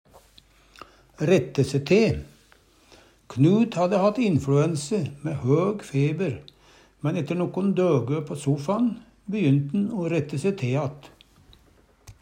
rette se te att - Numedalsmål (en-US)